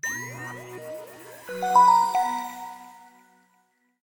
PowerOn.ogg